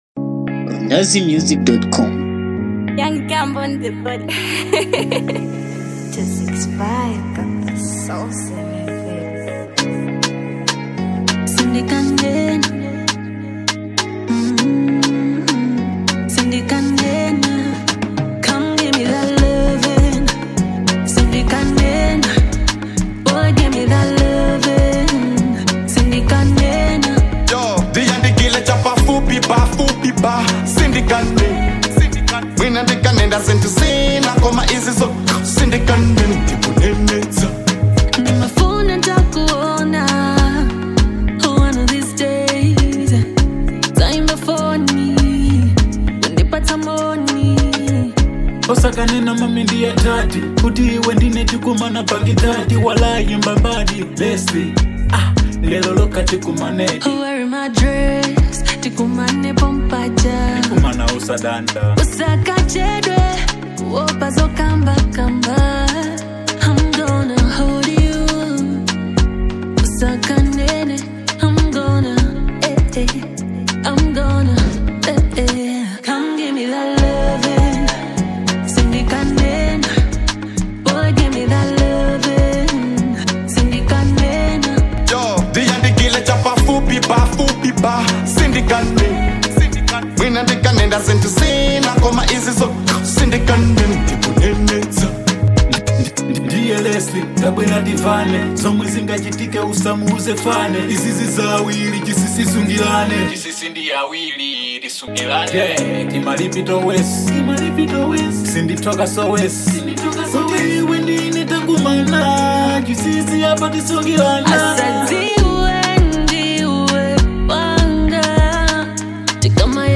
Malawian female singer